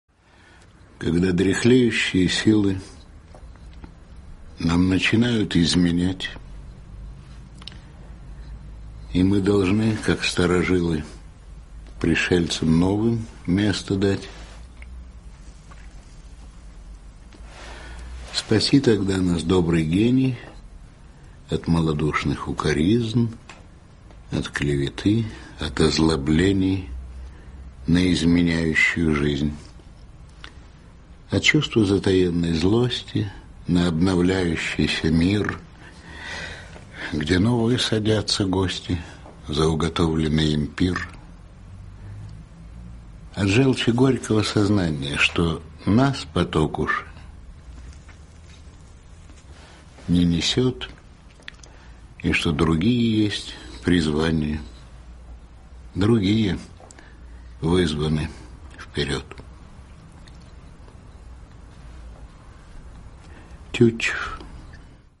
Стихотворение Тютчева «Когда дряхлеющие силы…» читает Леонид Броневой (скачать)